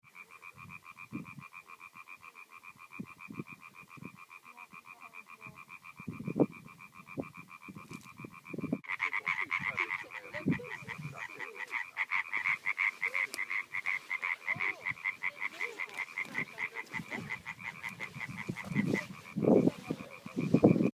Igår kväll anordnade SACT en grodexkursion till Frihult och Revingefältet.
Väl framme i Frihult höll lövgrodorna konsert. Det kväkte både här och där i vassen runt den lilla dammen.
grodor.mp3